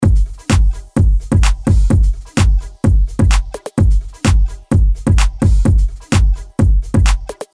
LP 035 – DRUM LOOP – HOUSE – 128BPM